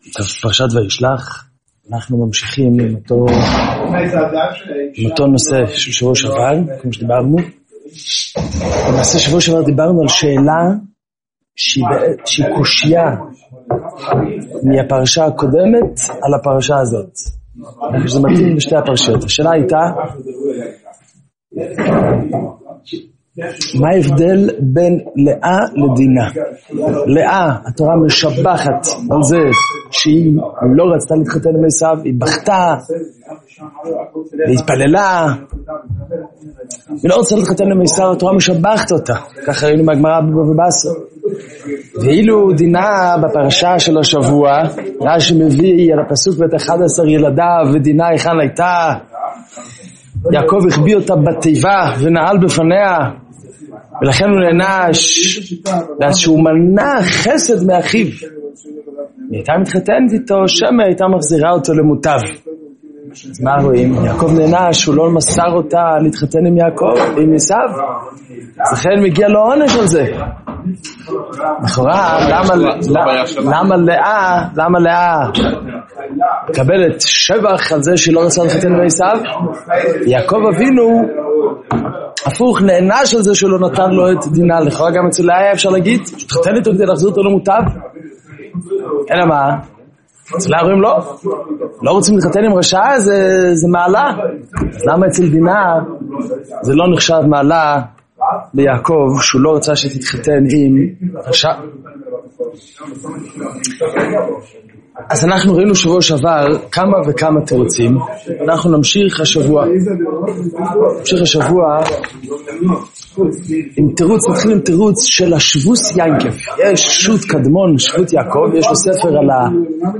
שיעור וישלח תשע”ו – יעקב נענש שמנע את דינה מעשו ואילו לאה נשתבחה שלא רצתה להינשא לו [ב]